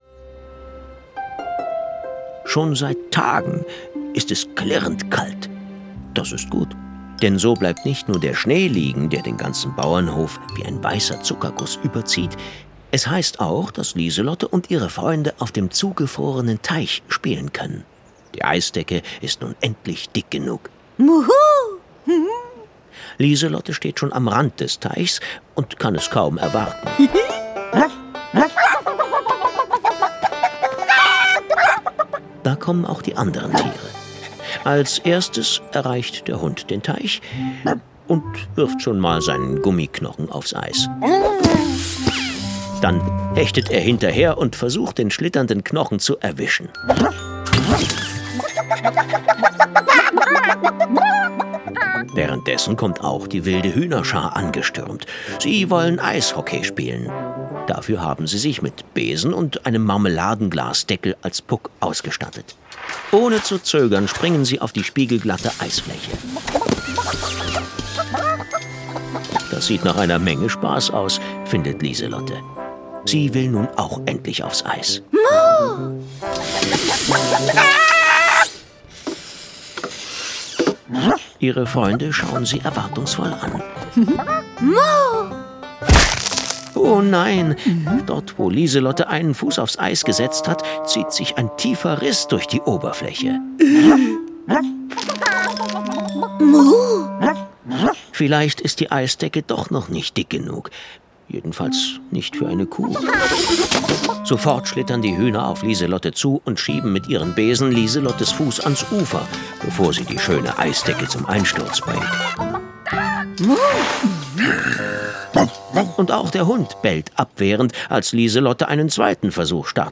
Weihnachtszeit mit Lieselotte Vier Hörspiele